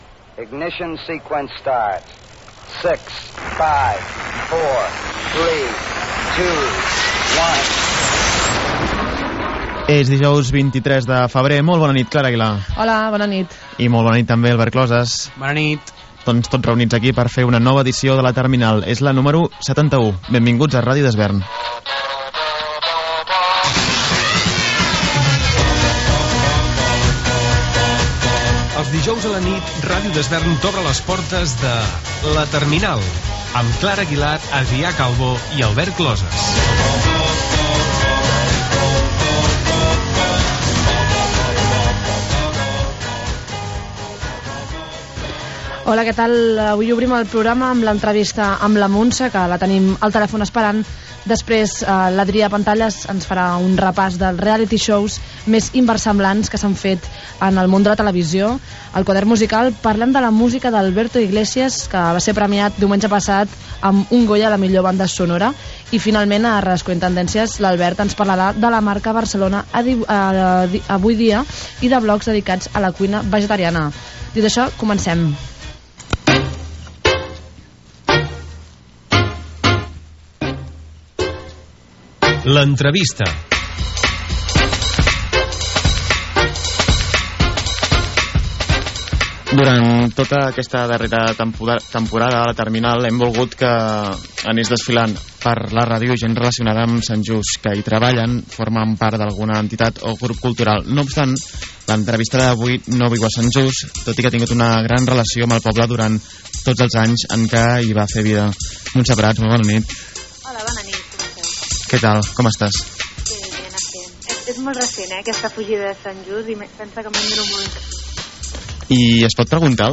Careta del programa, continguts i entrevista
Entreteniment
FM